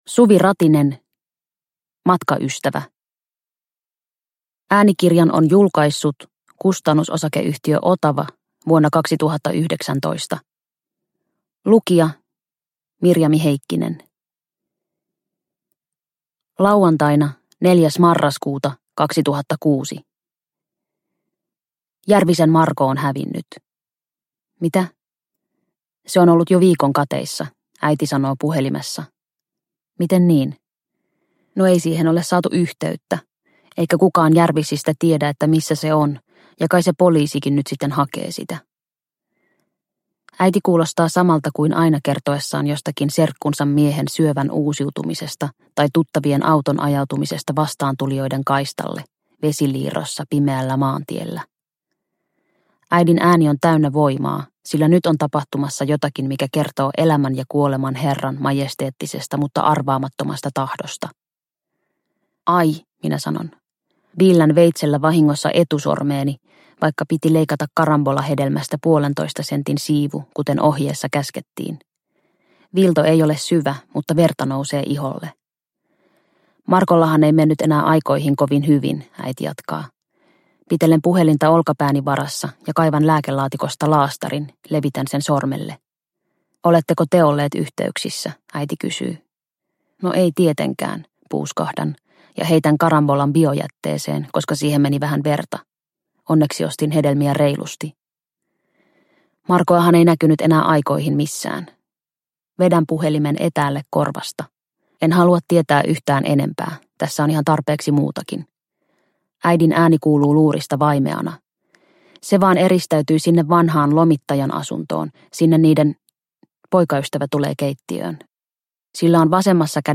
Matkaystävä – Ljudbok – Laddas ner